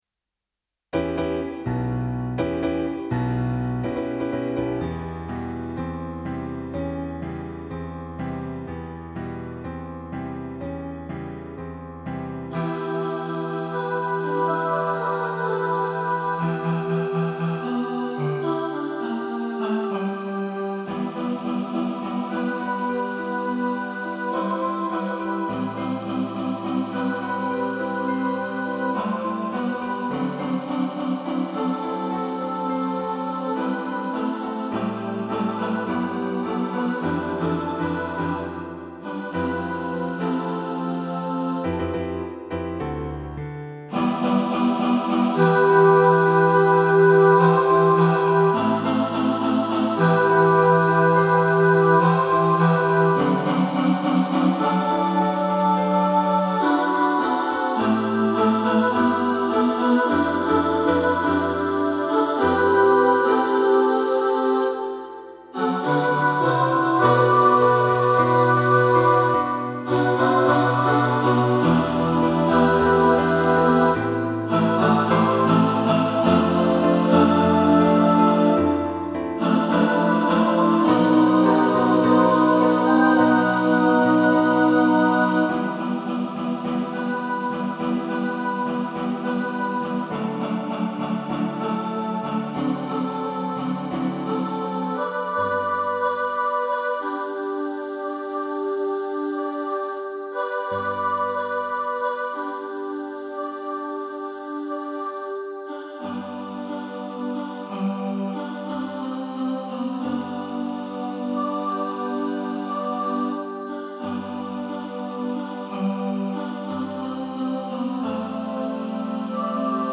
James Bond Medley (All voices) | Ipswich Hospital Community Choir